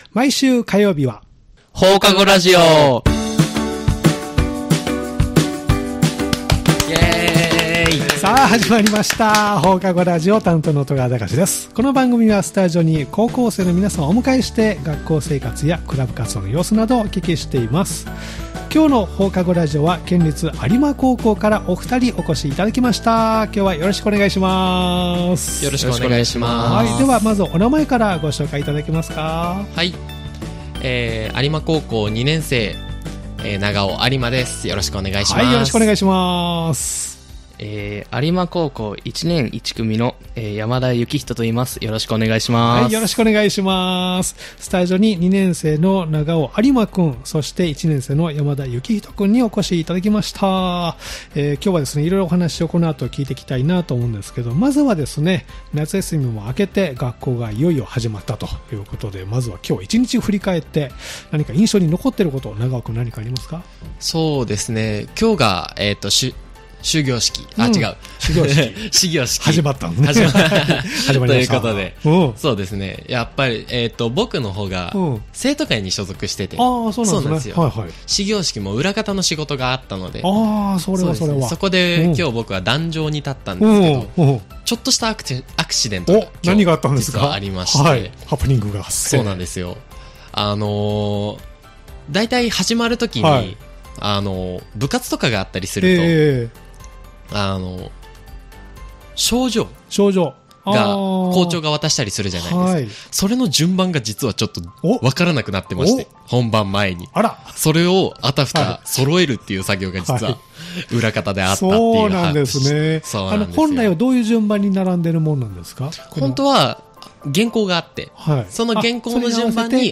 毎回高校生の皆さんをスタジオにお迎えして、学校生活、部活、学校行事などインタビューしています（再生ボタン▶を押すと放送が始まります）